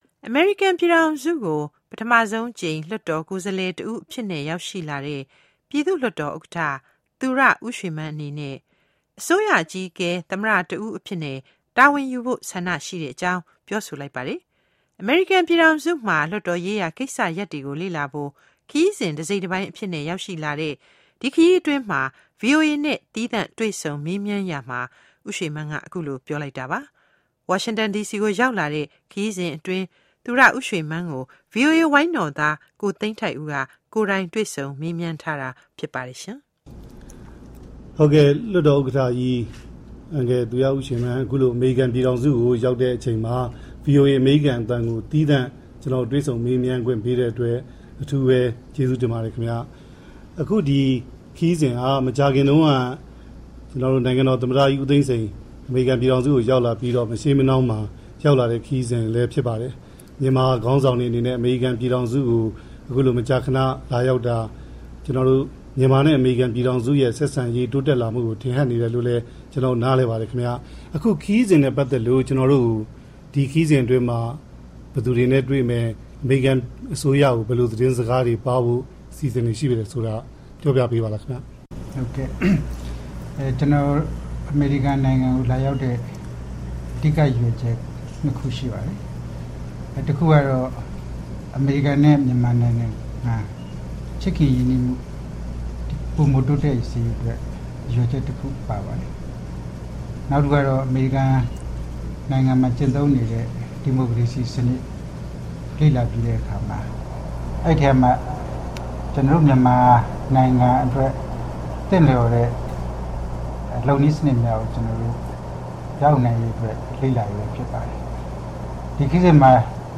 Itv with U Shwe Mann